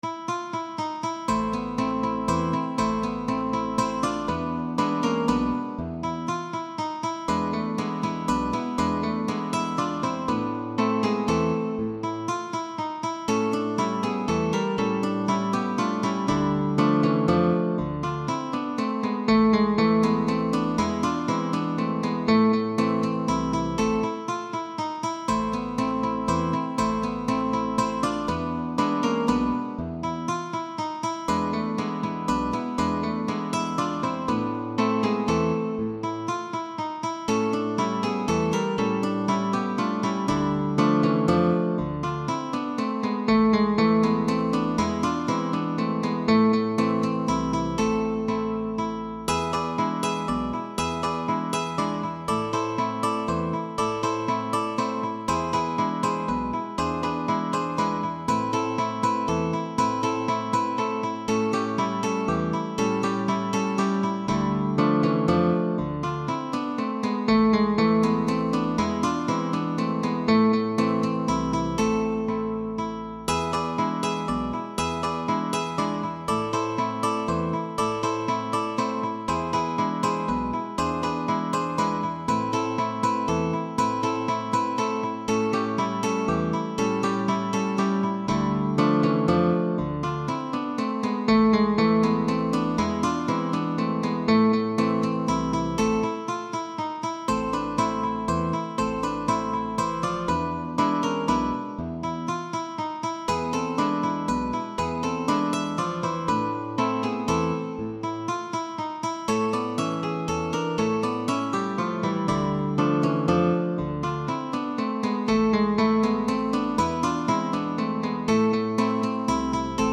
CUARTETO de GUITARRAS